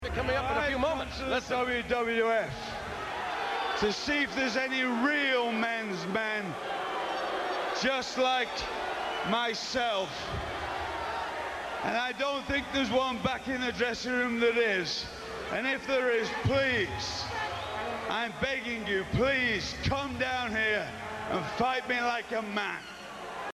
Slurring his words worse than Hawk, but for real, Regal issues and open challenge to anyone hoping to prove himself a real man’s man.
regal-slurs-1.mp3